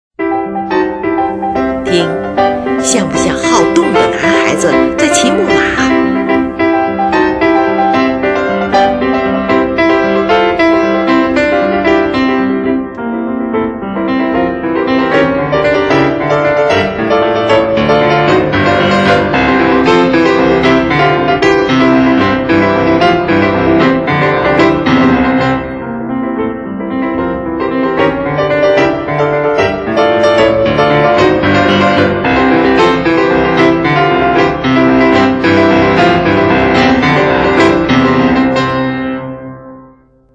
是钢琴艺术史上的一部极为独特的作品
乐曲始终保持着这样的节奏层次，它构成木马摇晃的节奏律动。而旋律在弱拍上的重音，再现了孩子玩耍时兴高采烈的神态。